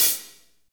HAT F R H22R.wav